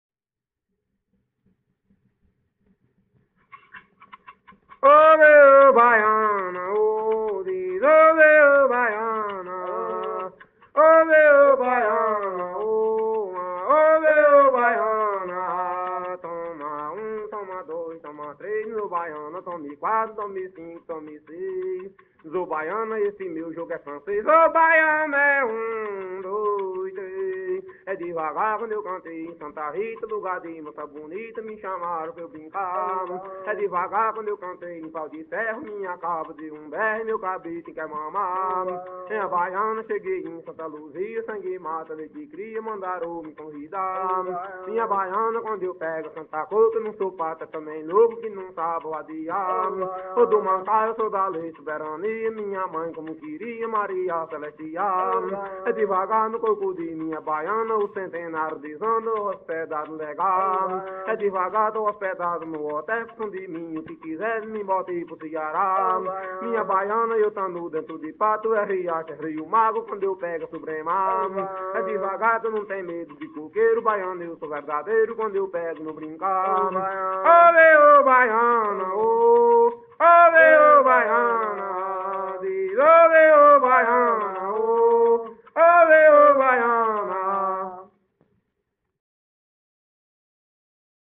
Coco embolada ""Baiana contada""